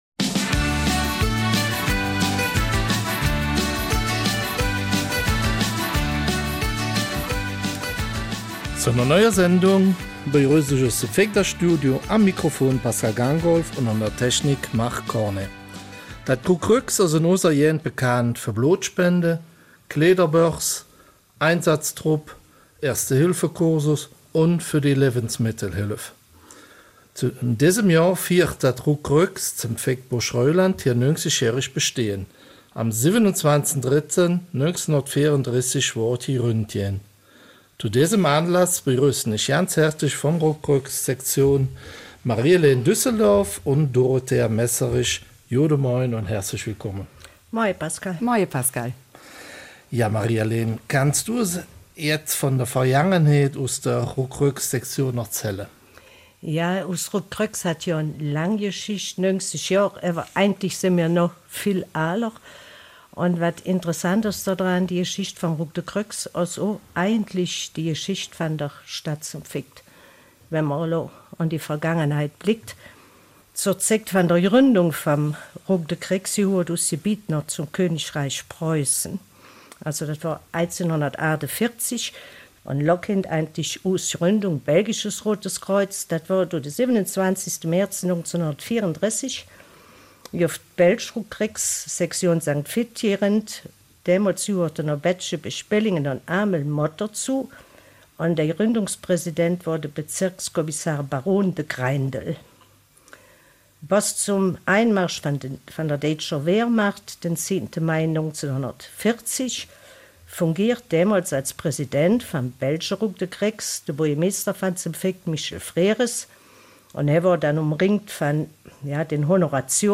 Eifeler Mundart: 90 Jahre Rot-Kreuz-Sektion St.Vith/Burg-Reuland